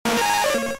Cri de Nidorina K.O. dans Pokémon Diamant et Perle.